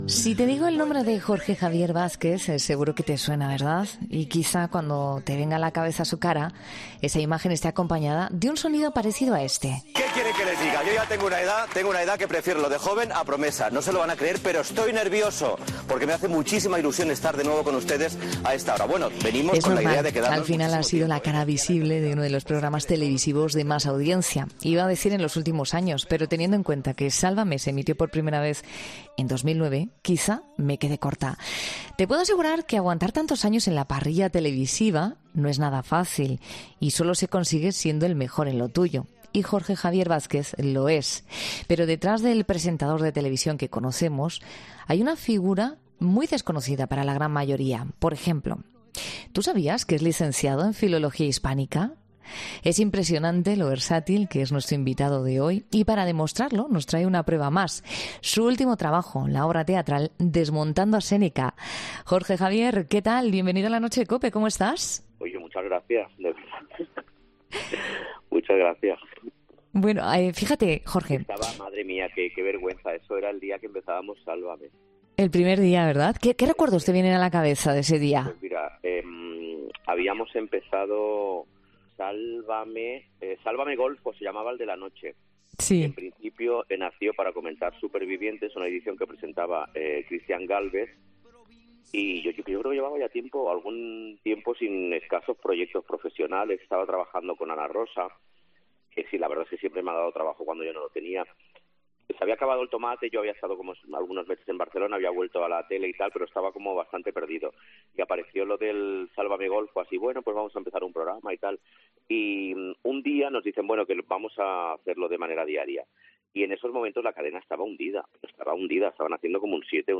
Jorge Javier Vázquez fue entrevistado en La Noche de COPE para hablar de su carrera y promocionar su nueva obra de teatro, 'Desmontando a Séneca'